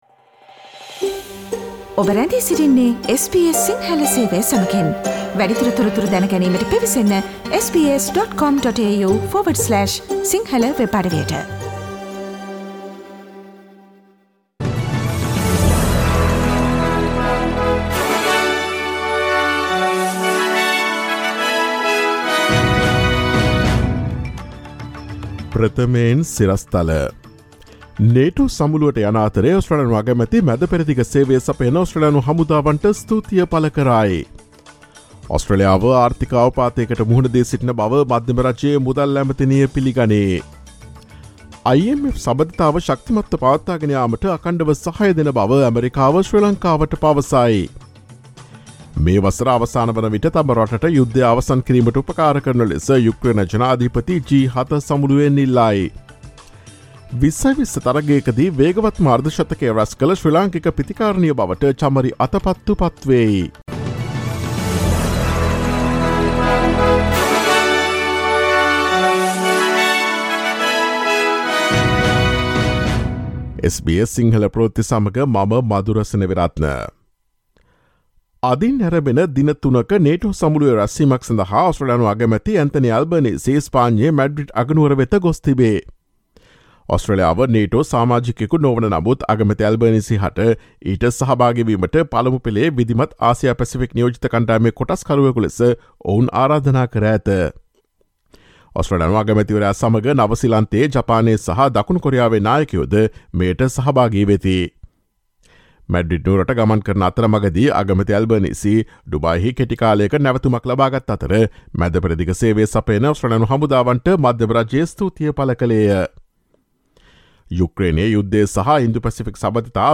ඔස්ට්‍රේලියාවේ සහ ශ්‍රී ලංකාවේ නවතම පුවත් මෙන්ම විදෙස් පුවත් සහ ක්‍රීඩා පුවත් රැගත් SBS සිංහල සේවයේ 2022 ජුනි 28 වන දා අඟහරුවාදා වැඩසටහනේ ප්‍රවෘත්ති ප්‍රකාශයට සවන් දීමට ඉහත ඡායාරූපය මත ඇති speaker සලකුණ මත click කරන්න.